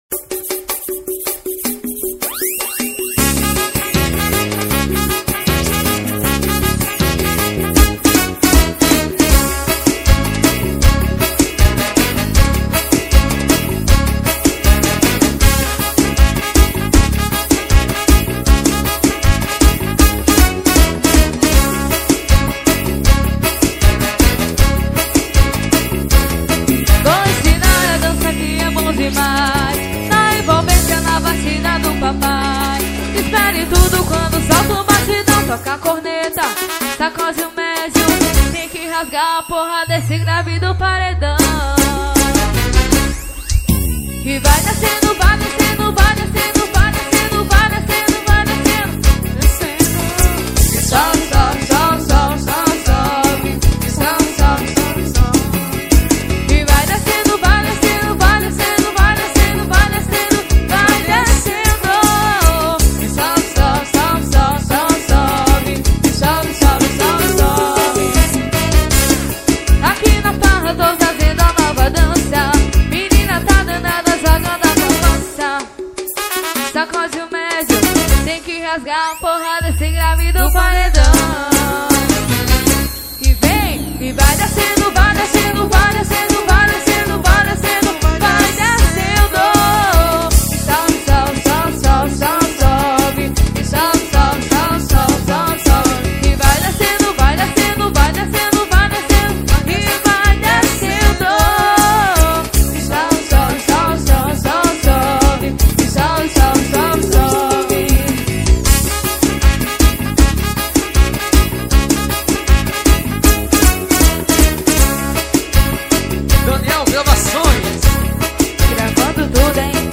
FORRO.